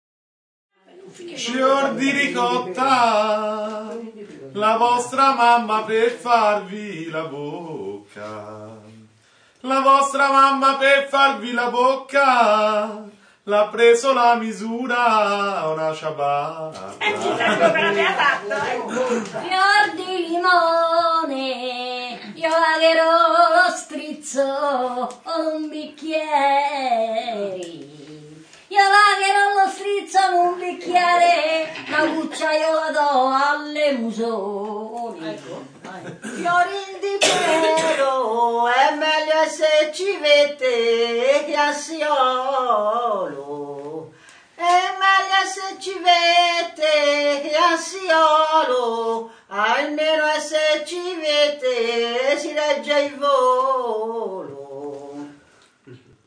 FARE AGLI STORNELLI
Podere Campicozzoli, Pontassieve (FI):